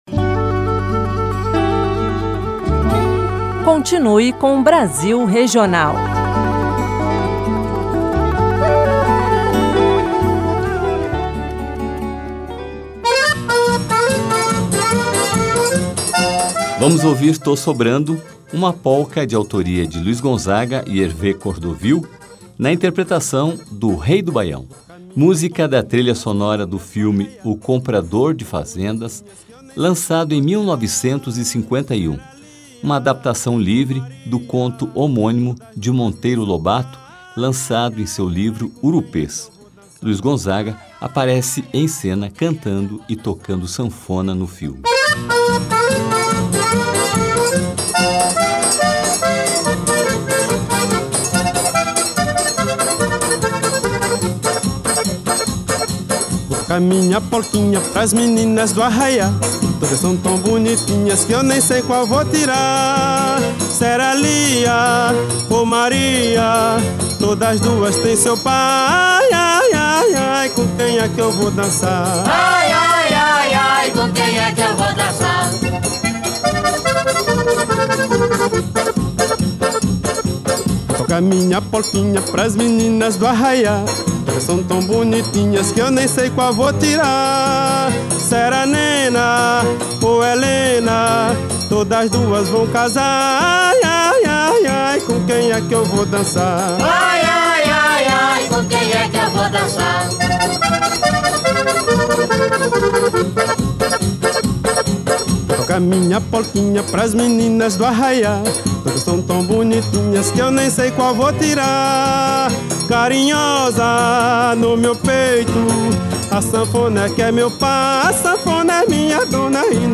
Música Brasileira